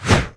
su_swing_3.wav